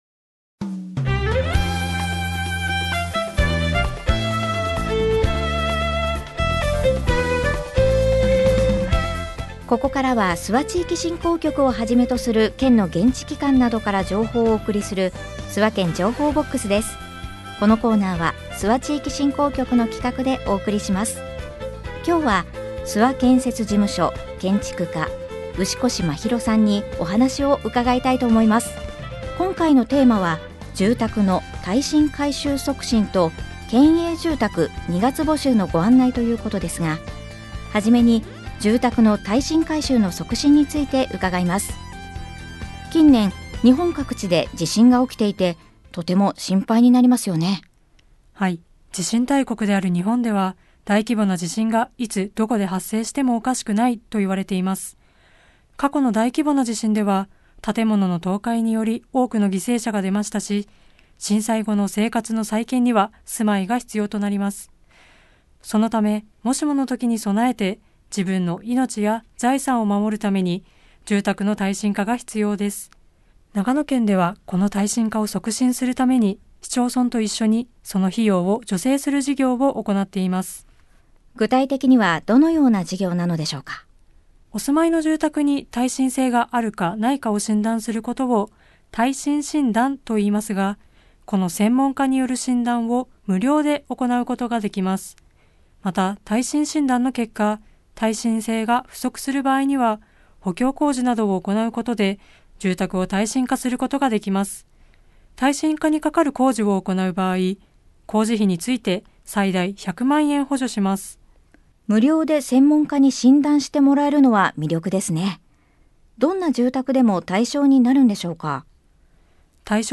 コミュニティエフエムを活用した地域情報の発信